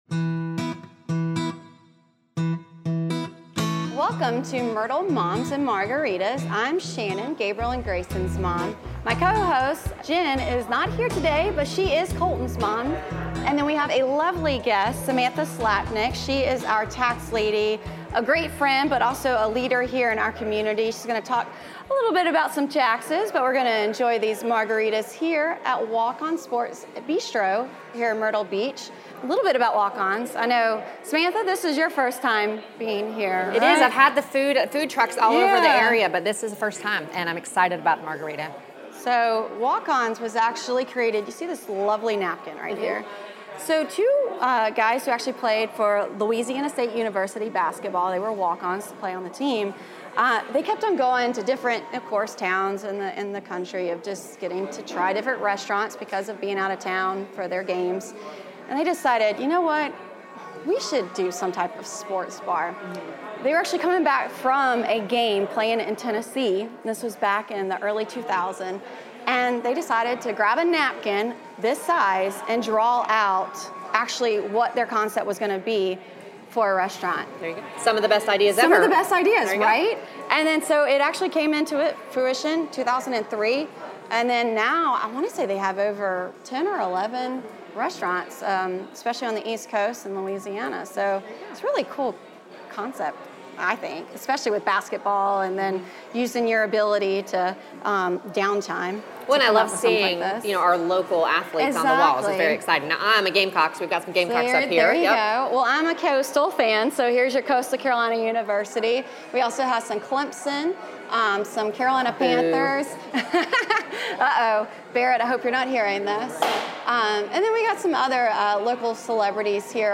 This episode was recorded at Walk On's Sports Bistreaux , where you can experience game day food in Myrtle Beach.